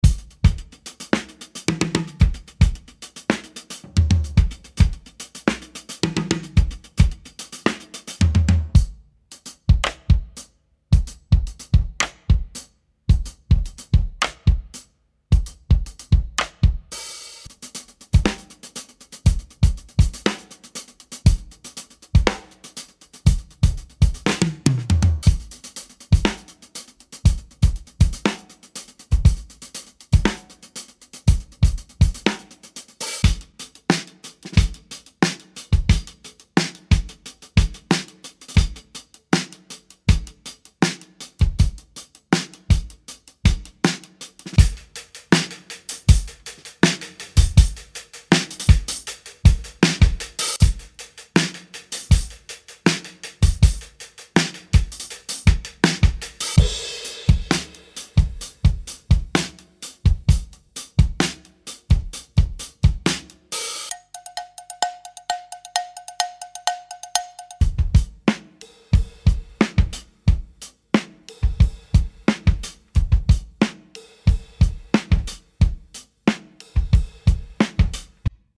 Chilled-Drums-3-demo.mp3